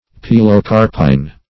Pilocarpine \Pi`lo*car"pine\, n. [From NL. Pilocarpus